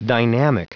Prononciation du mot dynamic en anglais (fichier audio)
Prononciation du mot : dynamic